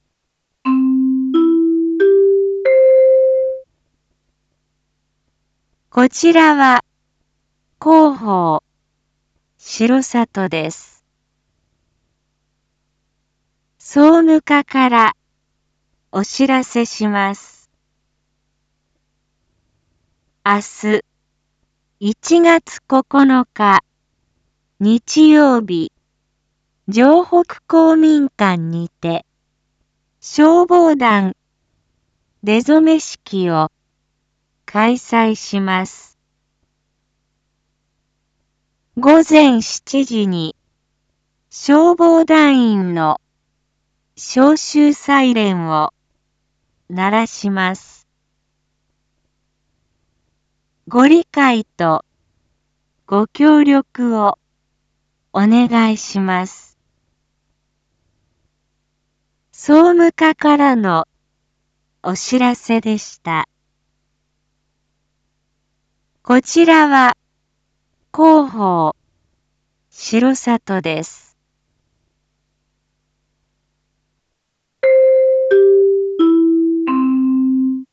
Back Home 一般放送情報 音声放送 再生 一般放送情報 登録日時：2022-01-08 19:01:19 タイトル：R4.1.8 午後7時 放送分 インフォメーション：こちらは広報しろさとです。